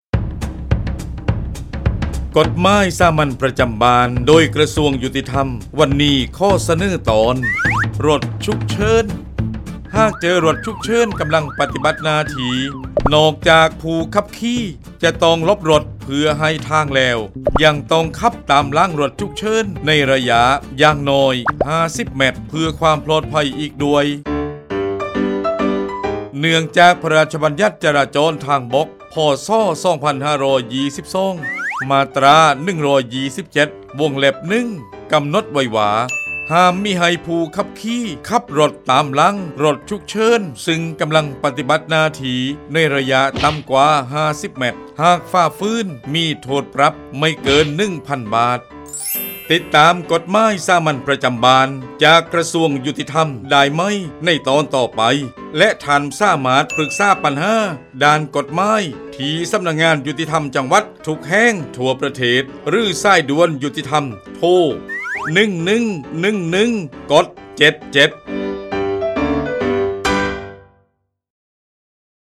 กฎหมายสามัญประจำบ้าน ฉบับภาษาท้องถิ่น ภาคใต้ ตอนรถฉุกเฉิน
ลักษณะของสื่อ :   บรรยาย, คลิปเสียง